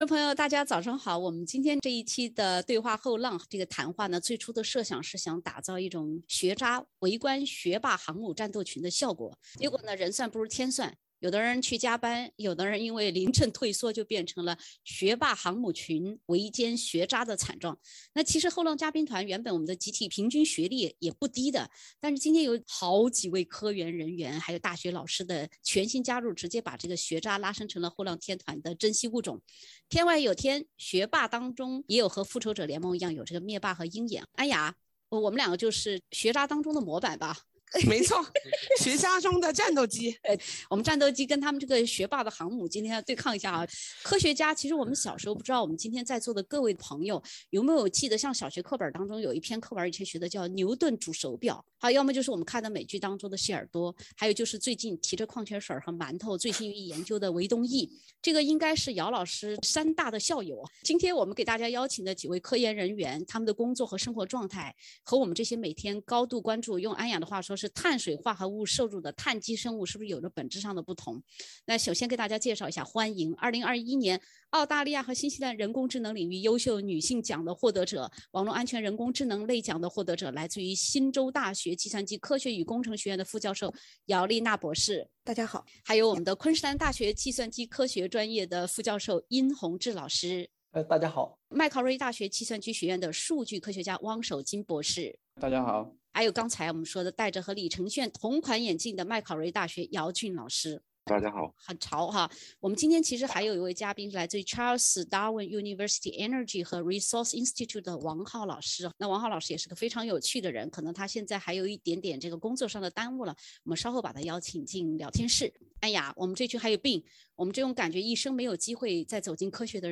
原本想打造学渣围观学霸航母战斗群的效果，结果变成了学霸航母集群围歼学渣。（点击封面图片，收听风趣对话）